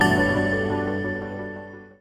menu-play-click.wav